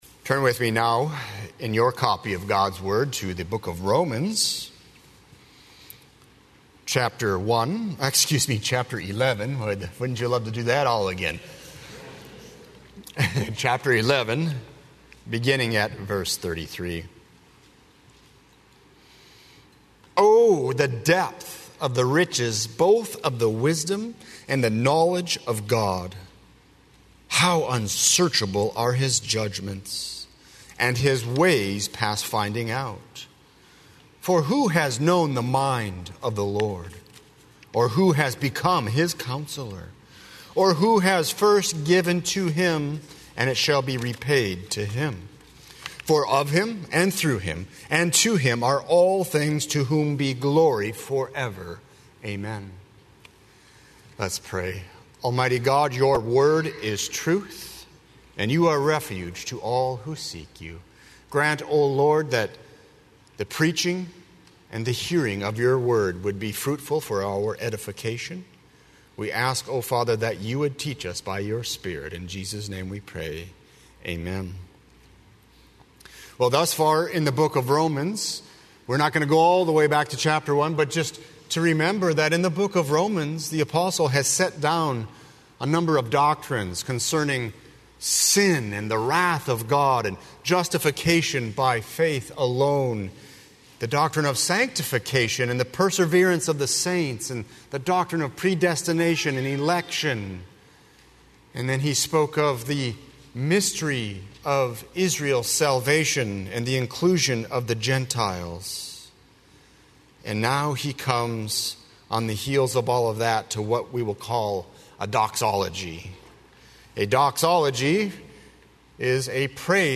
00:00 Download Copy link Sermon Text Romans 11:33–36